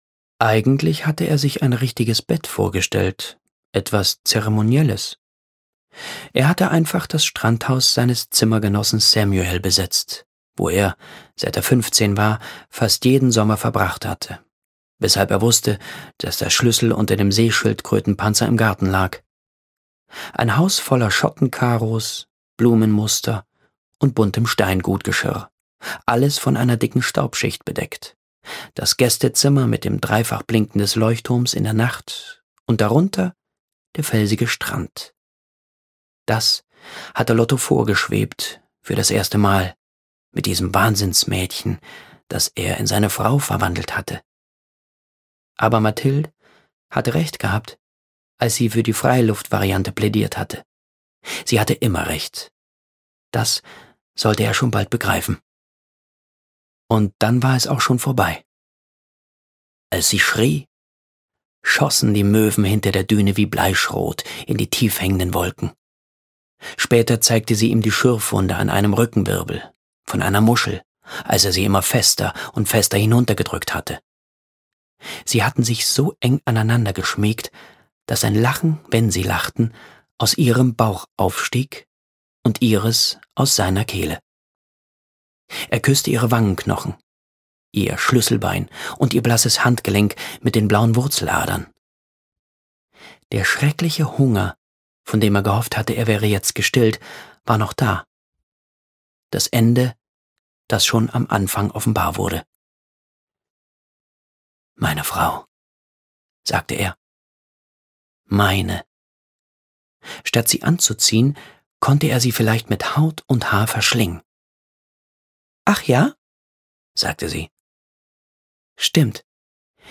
Hörbuch Licht und Zorn, Lauren Groff.